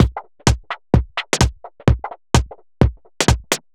Index of /musicradar/uk-garage-samples/128bpm Lines n Loops/Beats
GA_BeatEnvC128-03.wav